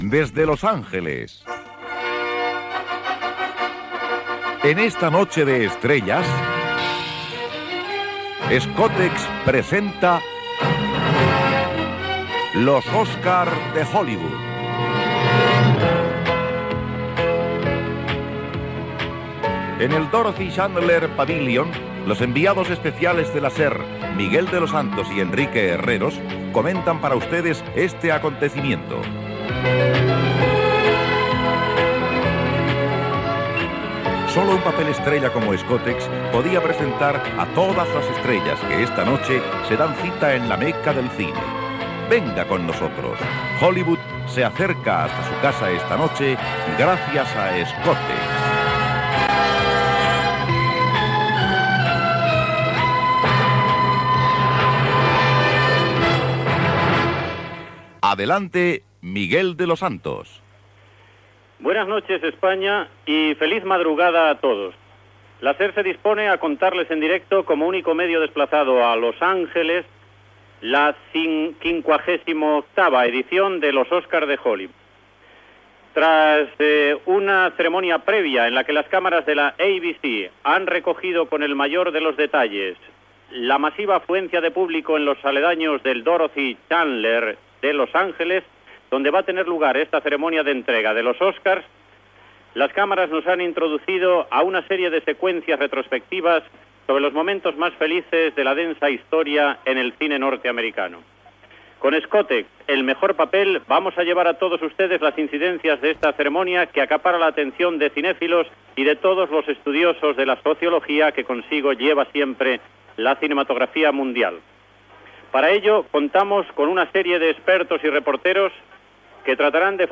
Transmissió, des del Dorothy Chandler Pavilion de Los Angeles, de la cerimònia de lliurament dels premis Oscar de cinema.
Careta del programa, presentació i primeres intervencions de l'equip.